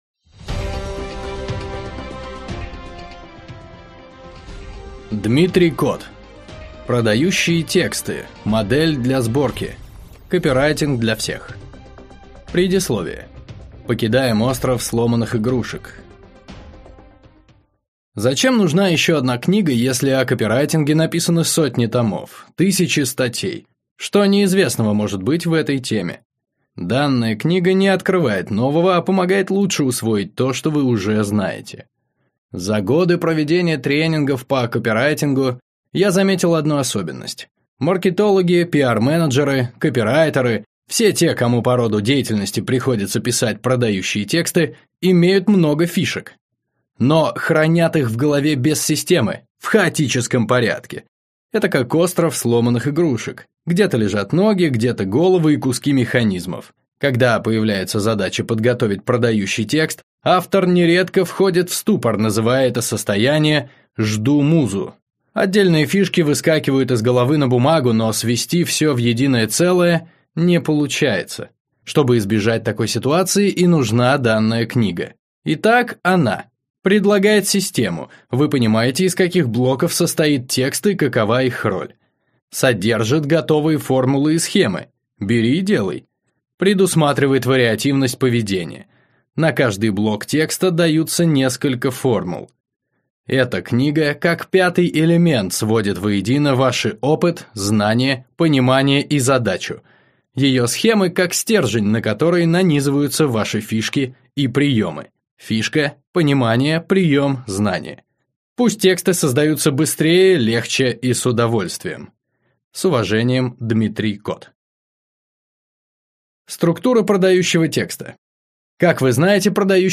Аудиокнига Продающие тексты. Модель для сборки. Копирайтинг для всех | Библиотека аудиокниг